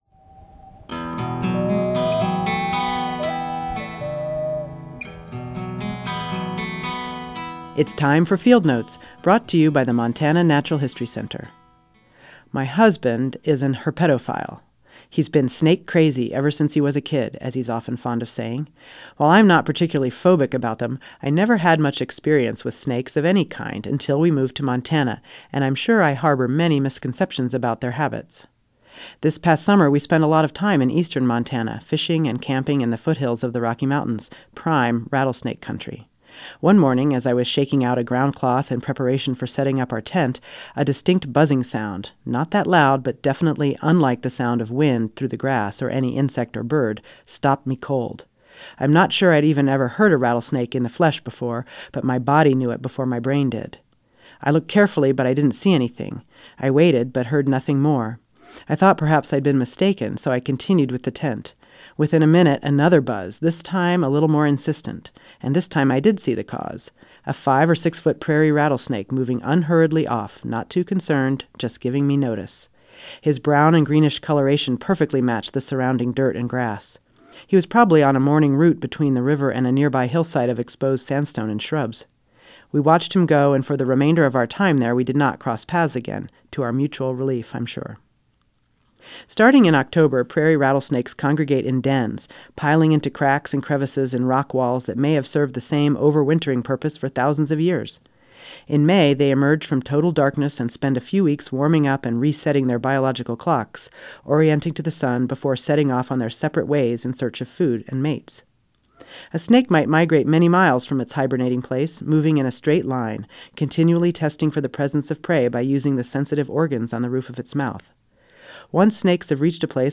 Field Notes are written by naturalists, students, and listeners about the puzzle-tree bark, eagle talons, woolly aphids, and giant puffballs of Western, Central and Southwestern Montana and aired weekly on Montana Public Radio.